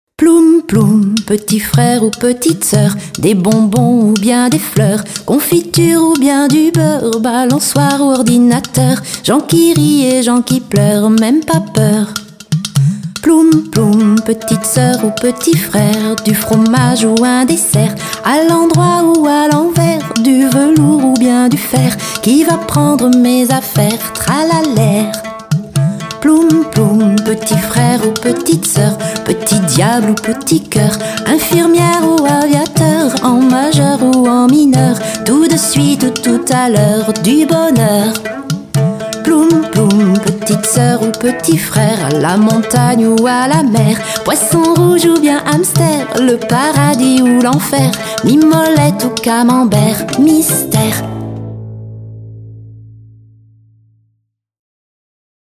Chanson « Ploum, ploum » :